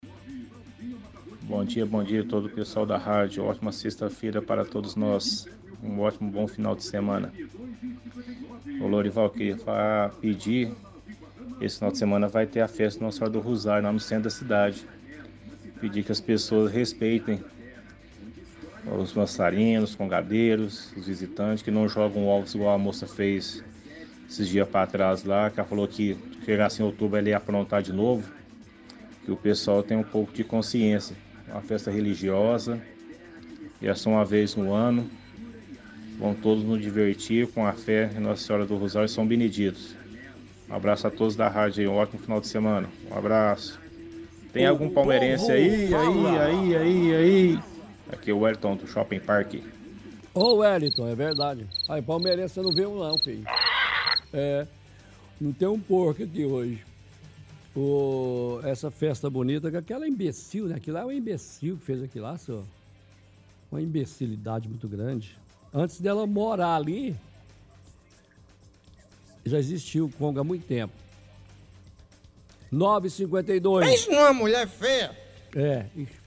– Ouvinte pede para que pessoas respeitem a festa do congado que acontece esse fim de semana na igreja do rosário.